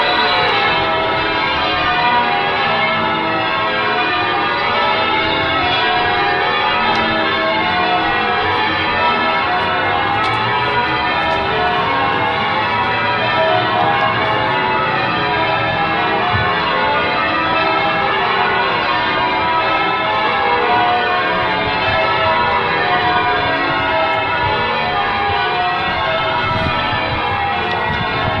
描述：在Baiona（Pontevedra）的Santa Liberata教堂记录铃声和铃声。2015年8月15日，18：16：33。MS侧微观水平：角度幅度为90度。
标签： 铃换质量 教堂 巴约纳 圣诞老人Liberata VAL-Minhor 铃声 钟声 现场录音
声道立体声